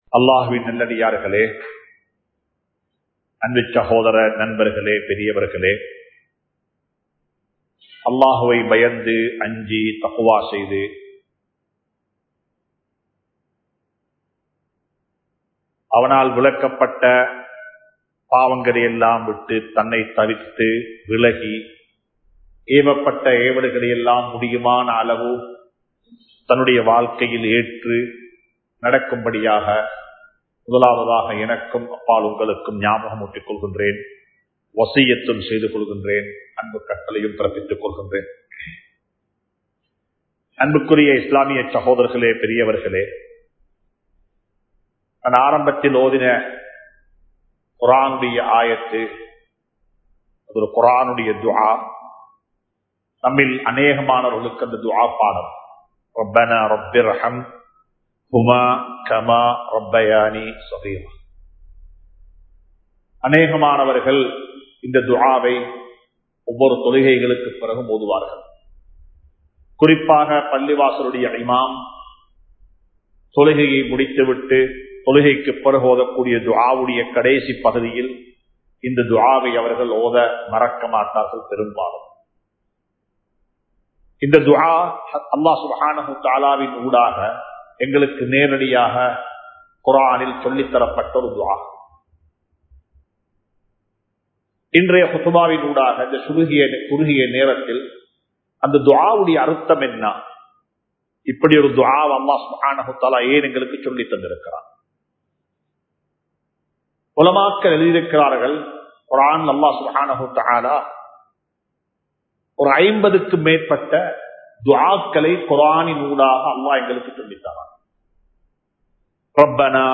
நல்லவர்கள் பிறப்பதில்லை வளர்க்கப்பட வேண்டும் (People won't be good themselves But have to be grown up) | Audio Bayans | All Ceylon Muslim Youth Community | Addalaichenai